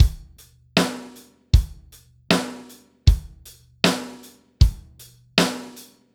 Track 07 - Drum Break 01.wav